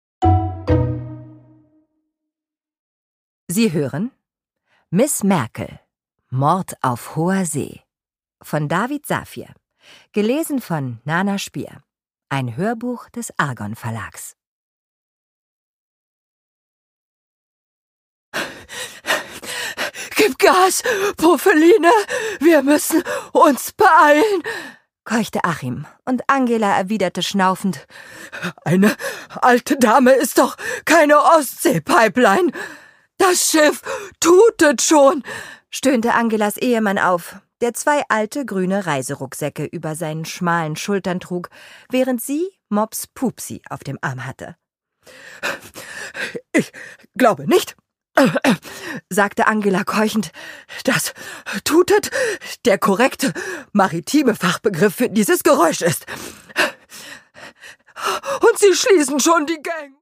Produkttyp: Hörbuch-Download
Gelesen von: Nana Spier
Denn ihre vielseitige Interpretationskunst und ihr Gespür für Witz und Timing kommen in dieser Reihe voll zum Tragen.